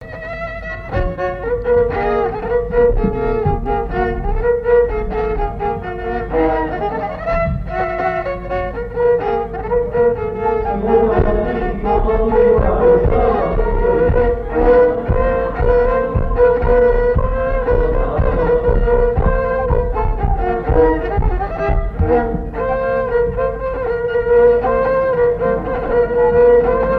Treize-Vents
danse : charleston
Assises du Folklore
Pièce musicale inédite